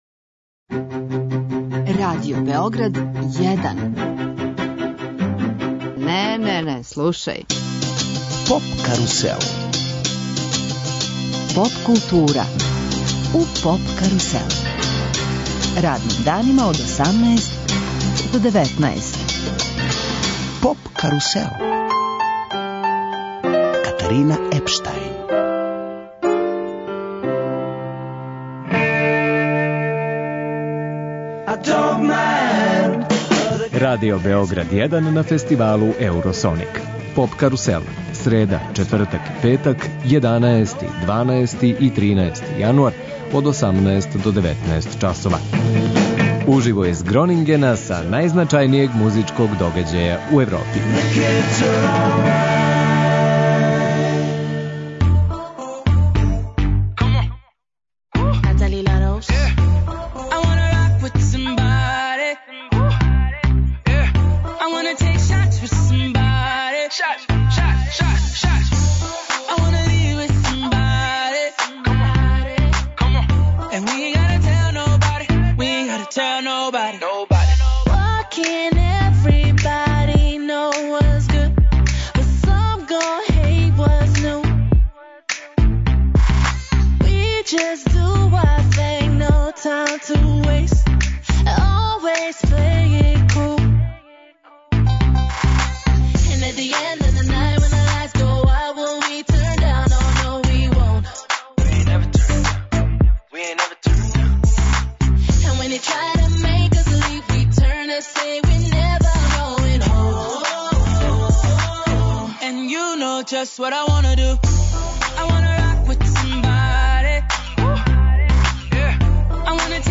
Радио Београд 1 ексклузивно емитује емисије уживо са водећег европског музичког фестивала Еуросоник.